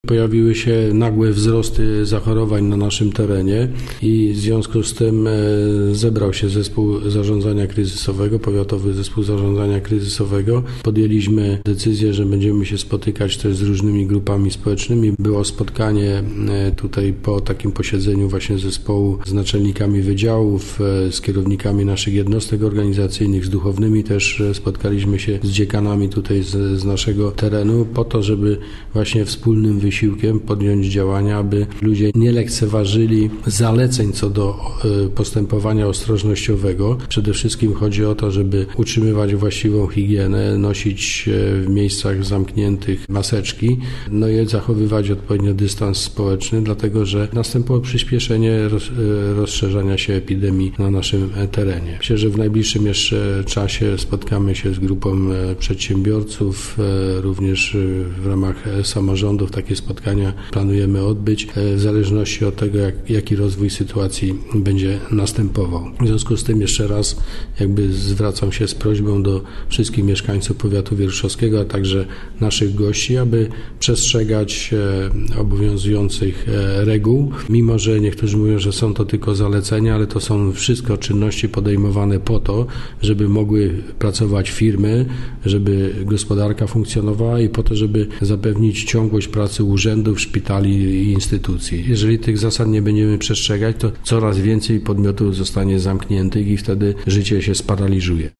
– mówił starosta powiatu wieruszowskiego, Andrzej Szymanek.